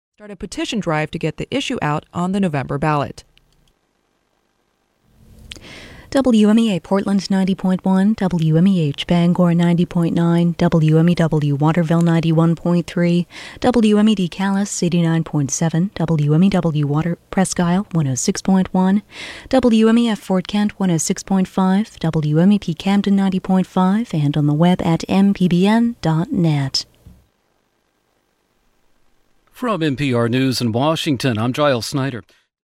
WMEA Top of the Hour Audio: